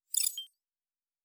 pgs/Assets/Audio/Sci-Fi Sounds/Weapons/Additional Weapon Sounds 1_1.wav at master
Additional Weapon Sounds 1_1.wav